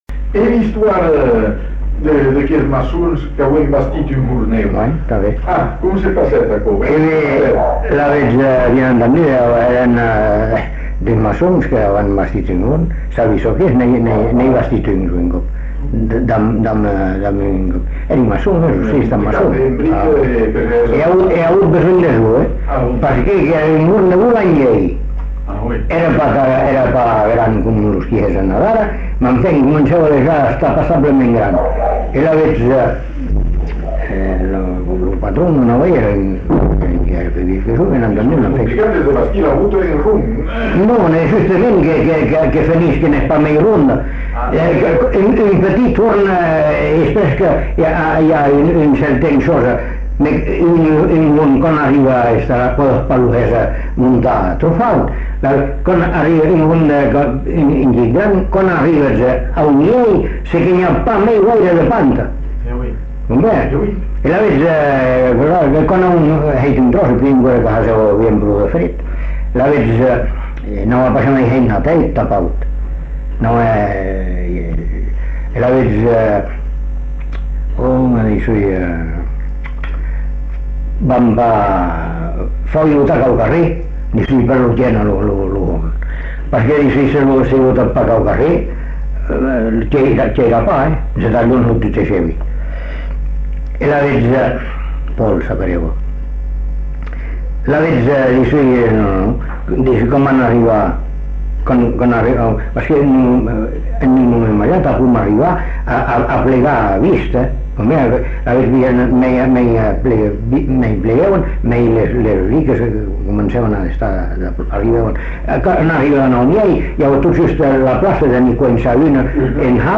Aire culturelle : Bazadais
Lieu : Bazas
Genre : conte-légende-récit
Effectif : 1
Type de voix : voix d'homme
Production du son : parlé